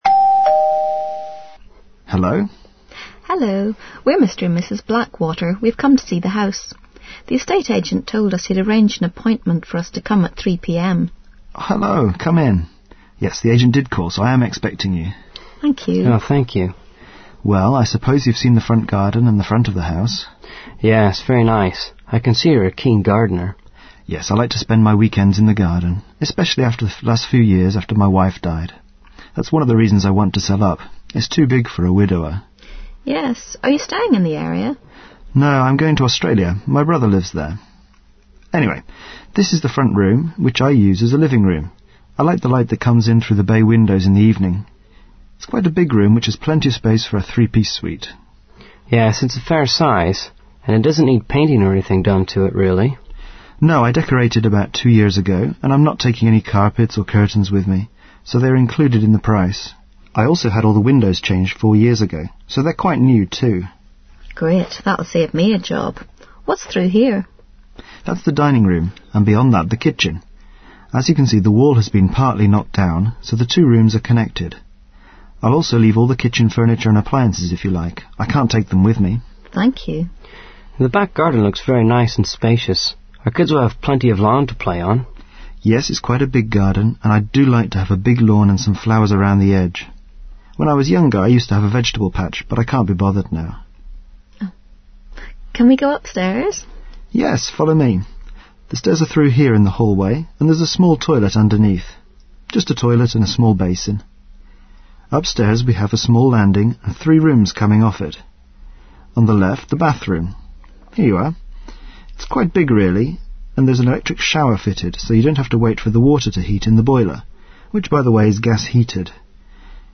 Scenki z udziałem 13 profesjonalnych lektorów o zróżnicowanym akcencie kształcą analizator słuchowy i jednocześnie wzbogacają słownictwo i utrwalają dobre wzorce językowe.
Przykładowe nagrania z ćwiczeń na zdaniach